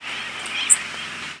Zeep calls
Worm-eating Warbler
The calls are short, typically between 1/20th and 1/10th of a second (50-100 mS) in duration, and high-pitched, typically between 6-10 kHz. The calls have an audible modulation that gives them a ringing, buzzy, or sometimes trilled quality.